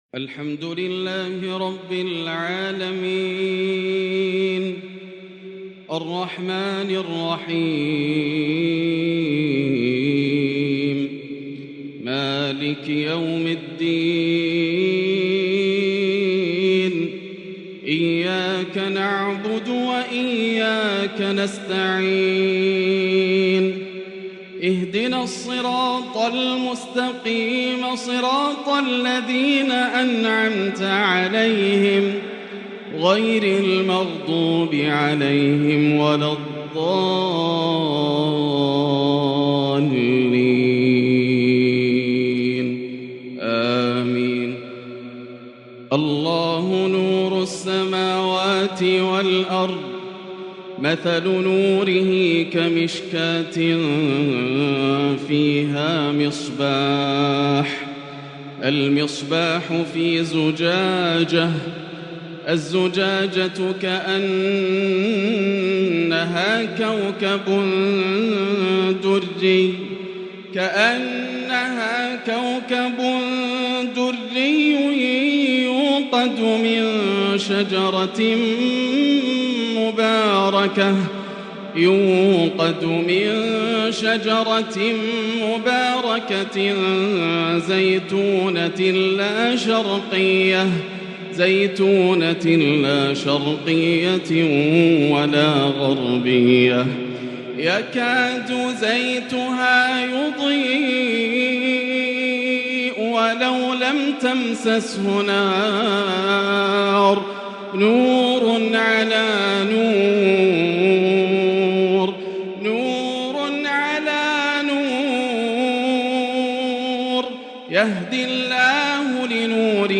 “الله نور السماوات والأرض” تغنّي مذهل بأداء جديد مميز للآسر د.ياسر الدوسري في تلاوات ذهبية > مقتطفات من روائع التلاوات > مزامير الفرقان > المزيد - تلاوات الحرمين